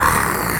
pgs/Assets/Audio/Animal_Impersonations/lizard_hurt_hiss_01.wav at master
lizard_hurt_hiss_01.wav